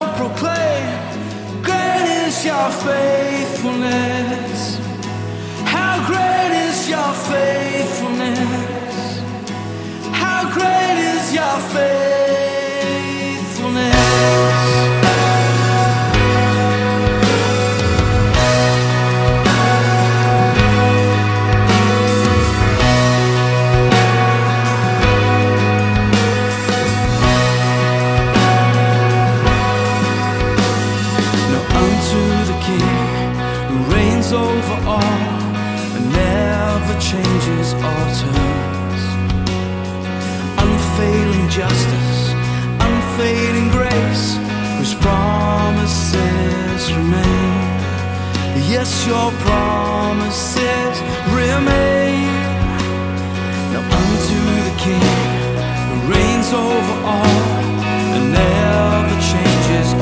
Live Worship...
• Sachgebiet: Praise & Worship